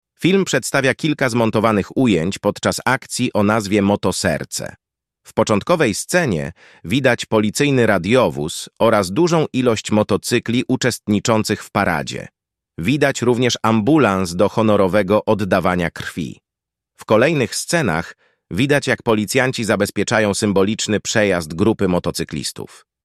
Nagranie audio audiodyskrypcja_motoserce.mp3